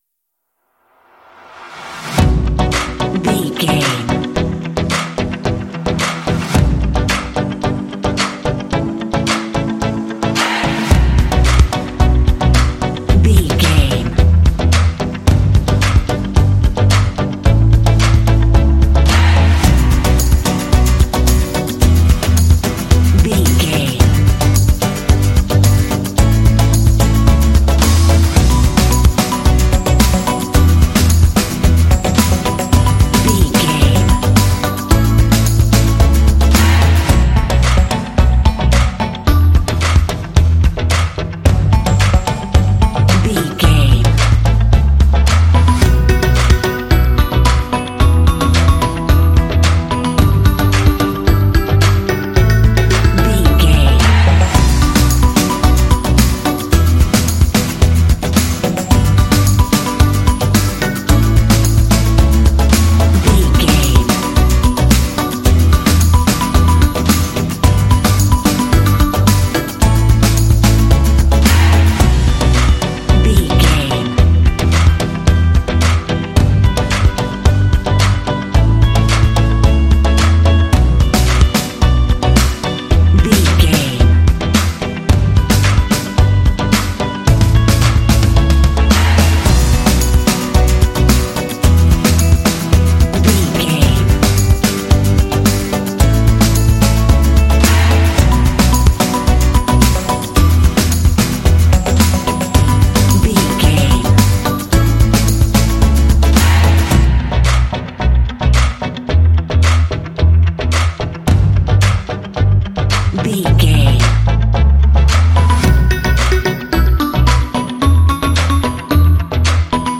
Aeolian/Minor
energetic
motivational
dramatic
synthesiser
drums
acoustic guitar
bass guitar
electric guitar
vocals
rock
alternative rock
indie